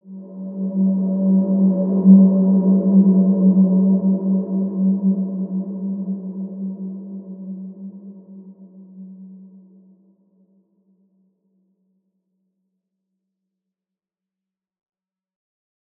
Large-Space-G3-mf.wav